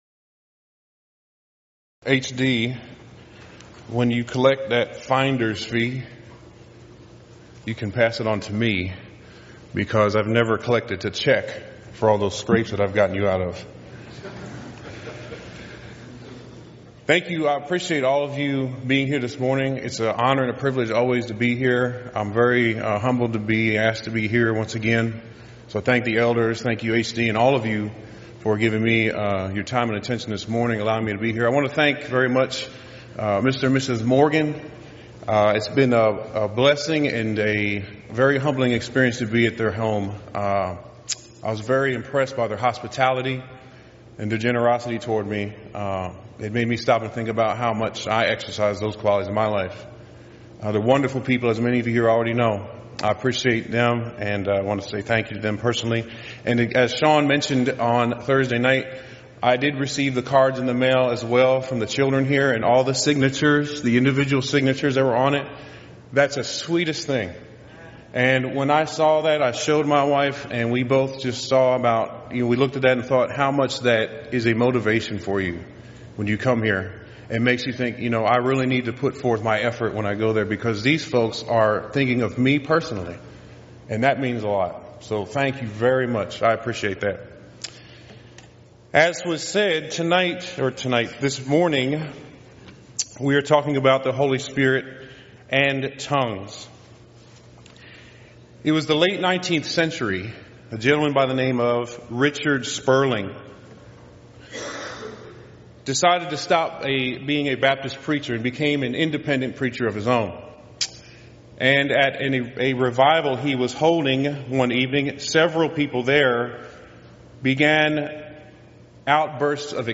Event: 23rd Annual Gulf Coast Lectures